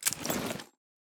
armor-close-3.ogg